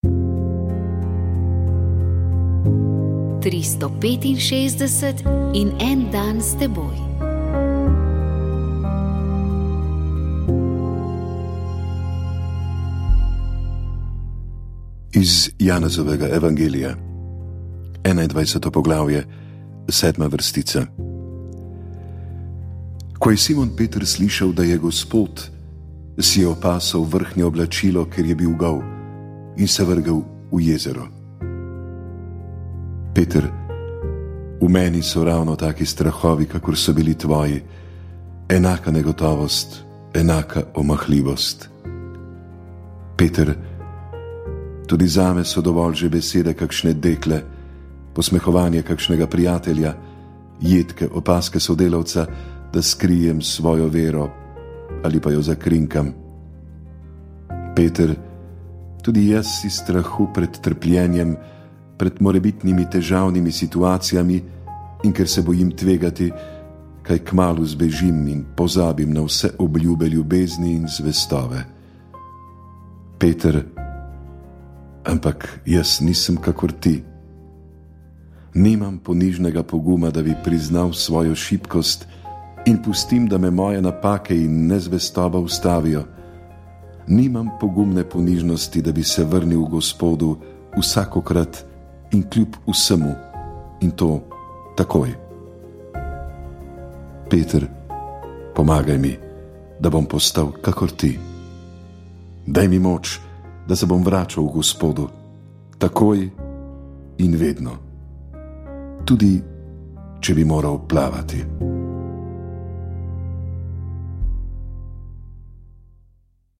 Naša gostja je bila direktorica Urada za stanje okolja dr. Nataša Sovič, ki je predstavila, kaj kažejo podatki in zakaj je dolgoročno spremljanje voda ključno za varovanje okolja.